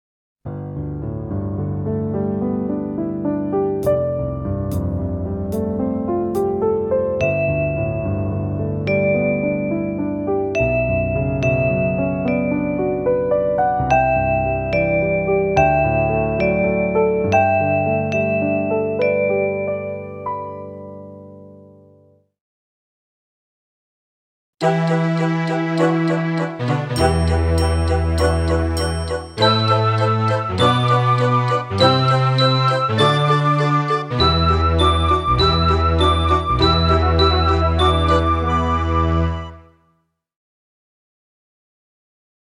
Extra soundmixbegeleidingen voor tutors instrument: